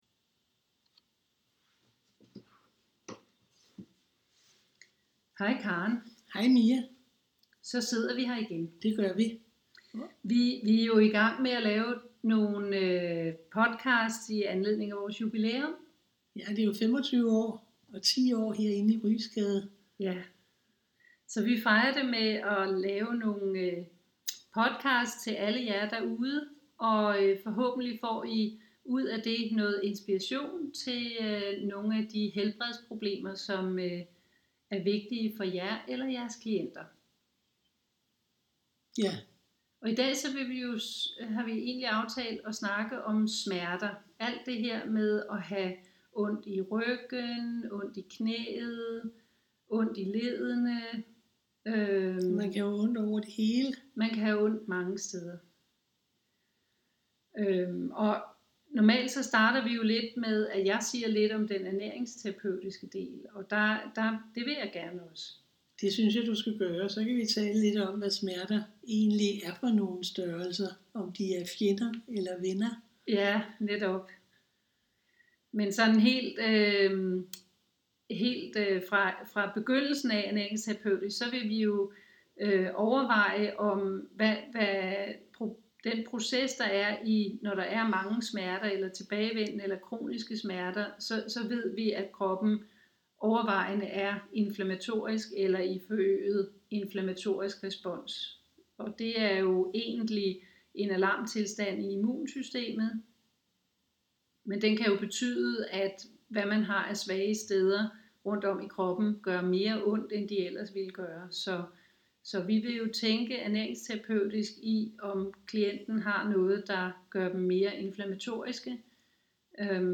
samtaler om smerte udfra en ernæringsterapeutisk og en kropsterapeutisk synsvinkel.